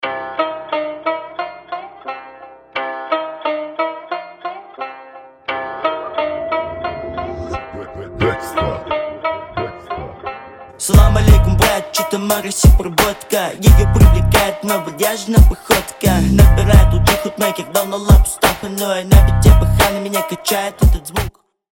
Хип-хоп
underground hip-hop